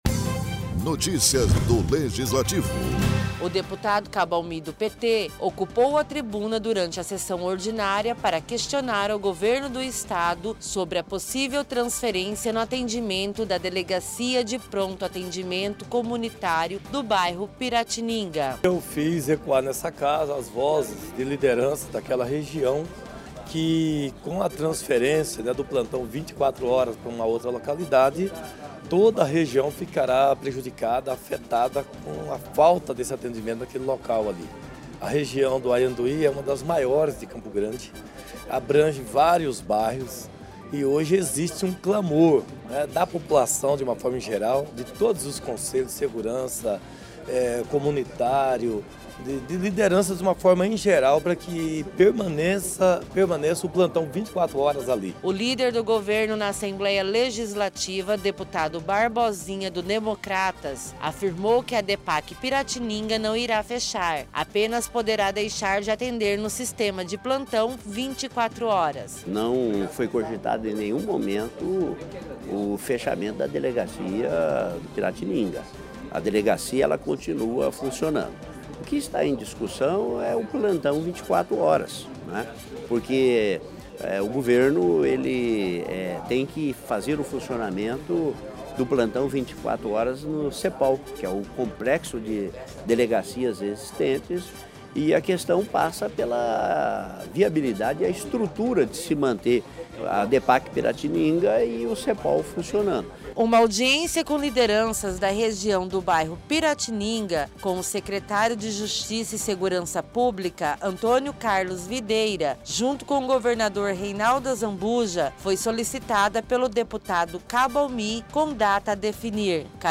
O deputado Cabo Almi, do PT ocupou a tribuna da Assembleia Legislativa para questionar o Governo do Estado sobre a possível transferência de atendimento da Delegacia de Pronto Atendimento Comunitário (Depac) localizada no bairro Piratininga.